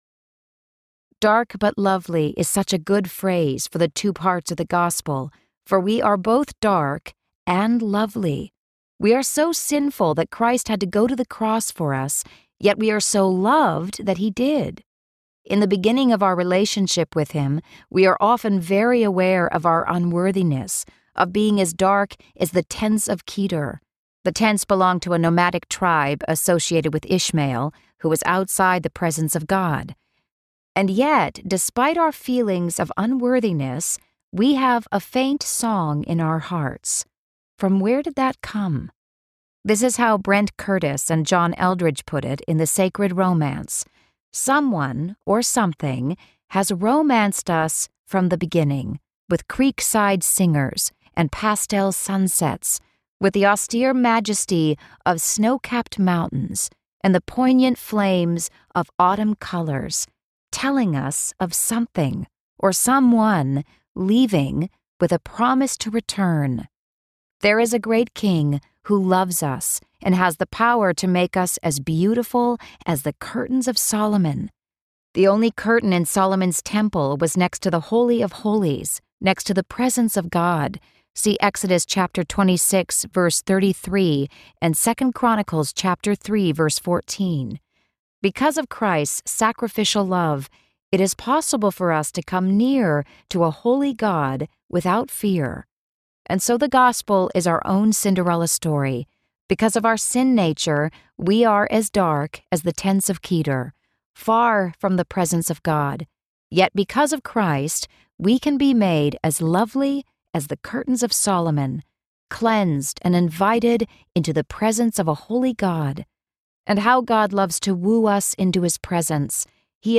He Calls You Beautiful Audiobook
Narrator
5.15 Hrs. – Unabridged